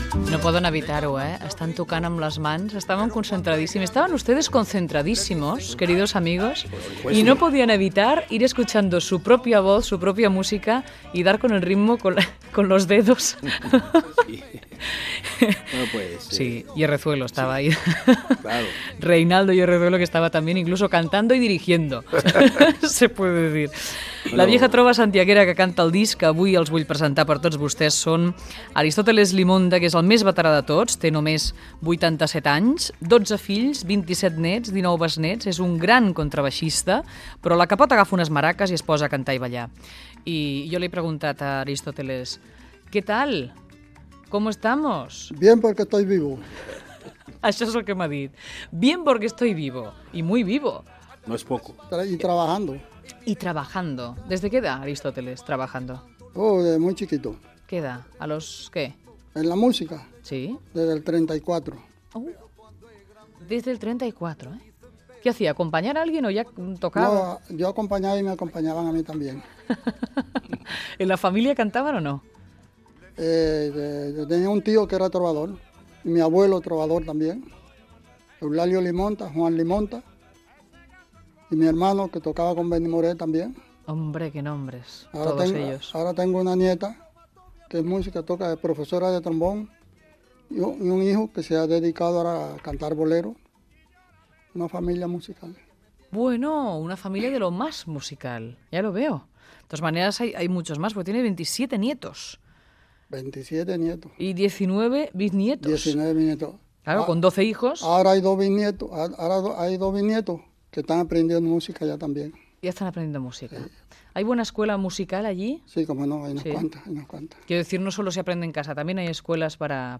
Fragment d'una entrevista amb el grup La Nova Trova Santiaguera.
Entreteniment